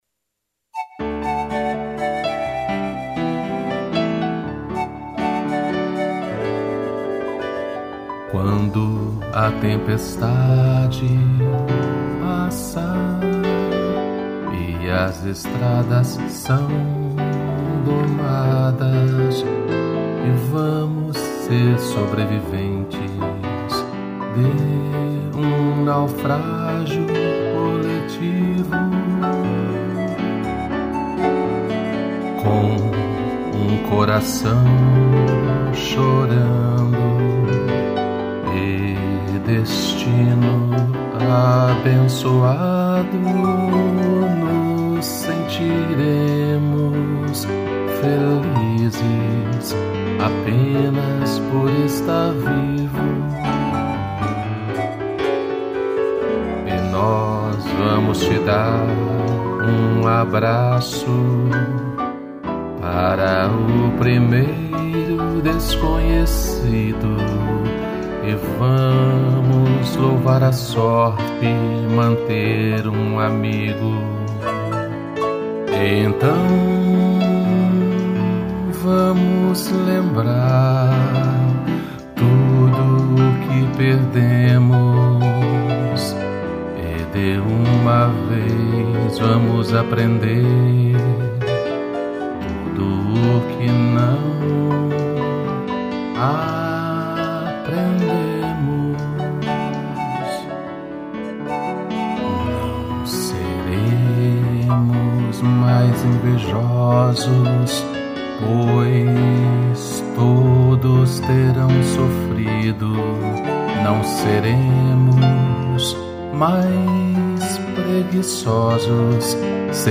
2 pianos e flauta pan